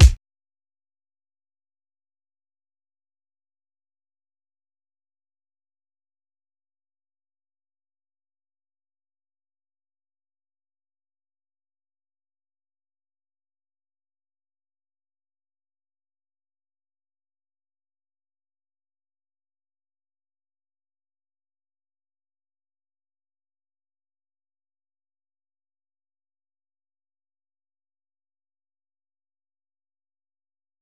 Kick